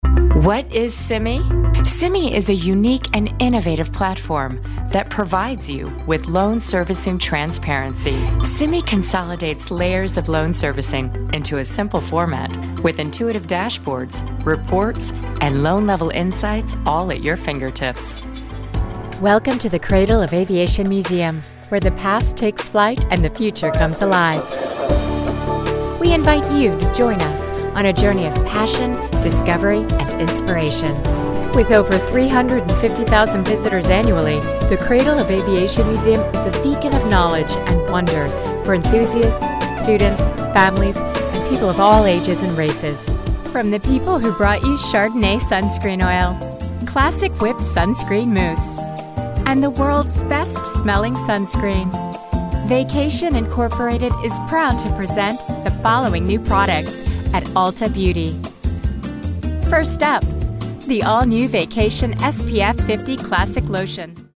An evocative, authoritative, sunny voice.
Corporate Narration
classy, confident, corporate, informative, narrative, professional, Straight Forward